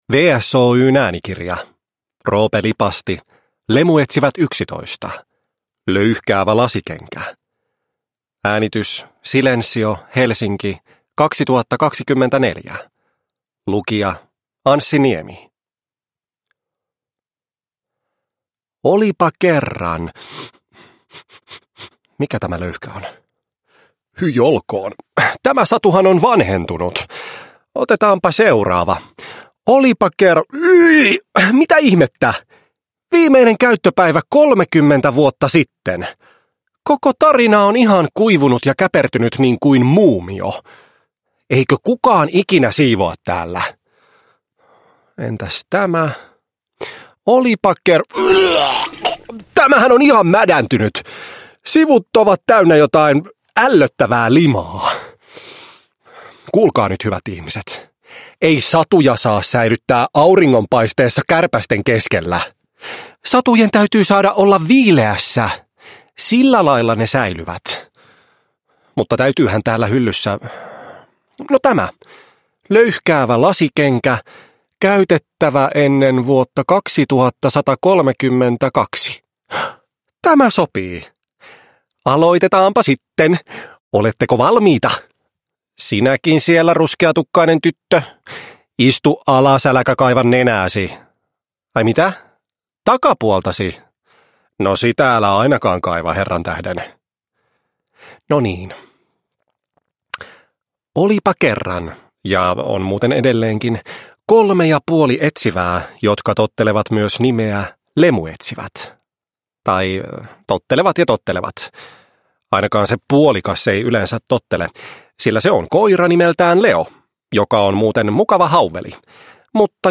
Lemuetsivät 11: Löyhkäävä lasikenkä (ljudbok) av Roope Lipasti